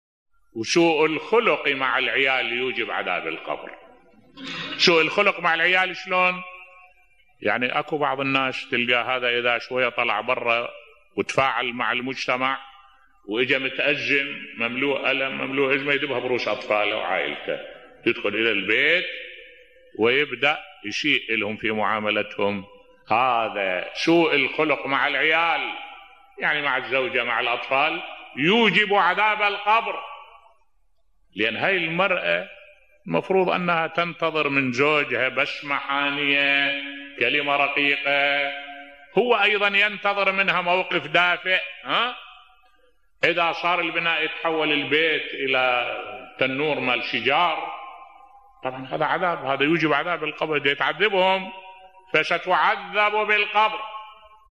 ملف صوتی سوء الخلق مع العيال يوجب عذاب القبر بصوت الشيخ الدكتور أحمد الوائلي